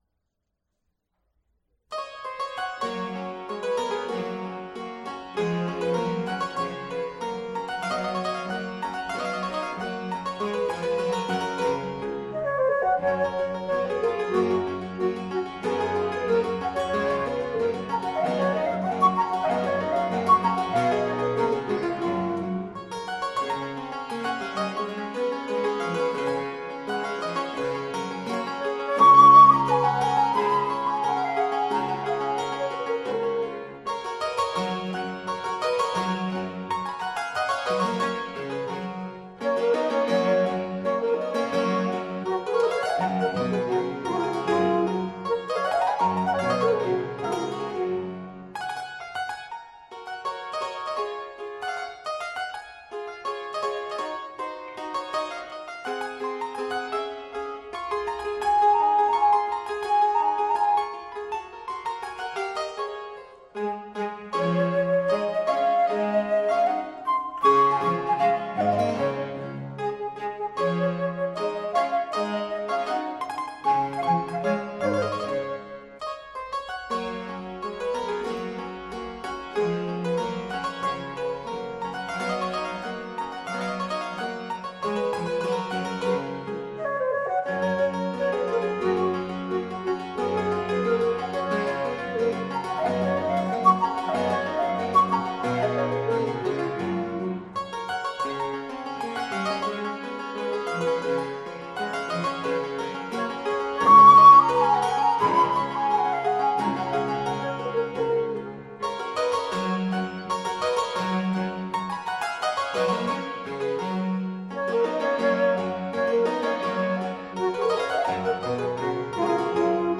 PerformerThe Raritan Players
Subject (lcsh) Sonatas